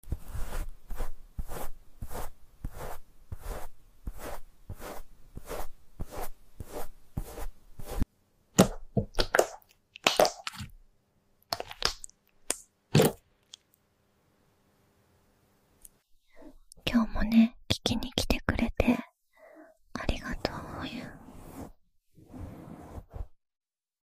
She’s just doing ASMR— brushing,